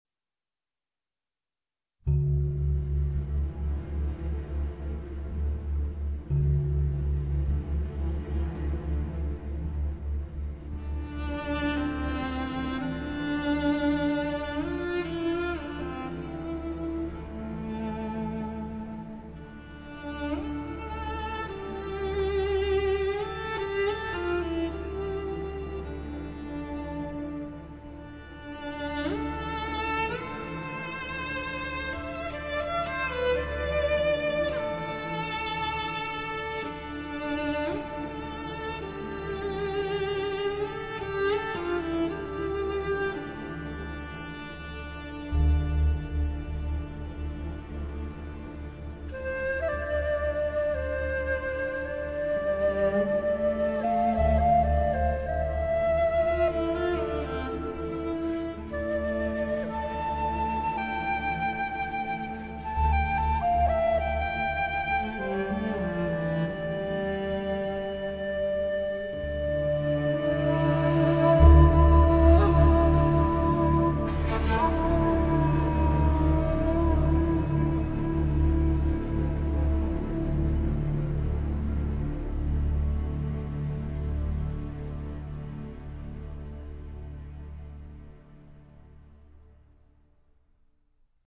В том числе и благодаря красивой инструментальной музыке.